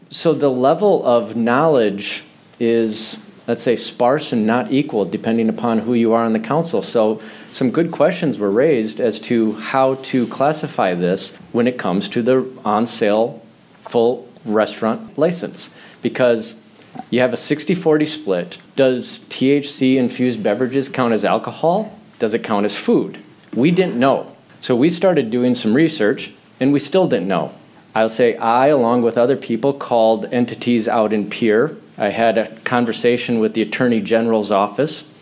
Mayor Ried Holien opened remarks…